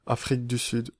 Ääntäminen
Synonyymit République d'Afrique du Sud Ääntäminen France (Lyon): IPA: [a.fʁik dy syd] Haettu sana löytyi näillä lähdekielillä: ranska Käännös Erisnimet 1.